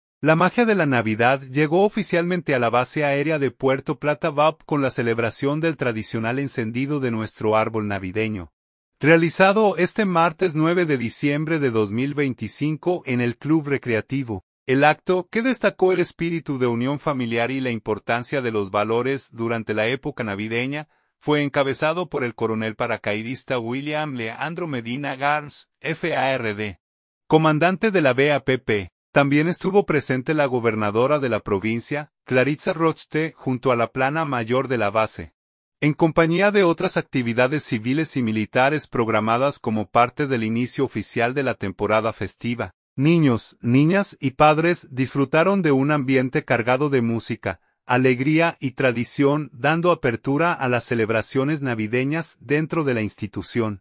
Puerto Plata.– La magia de la Navidad llegó oficialmente a la Base Aérea de Puerto Plata (BAPP) con la celebración del tradicional “Encendido de nuestro Árbol Navideño”, realizado este martes 9 de diciembre de 2025 en el Club Recreativo.
Niños, niñas y padres disfrutaron de un ambiente cargado de música, alegría y tradición, dando apertura a las celebraciones navideñas dentro de la institución.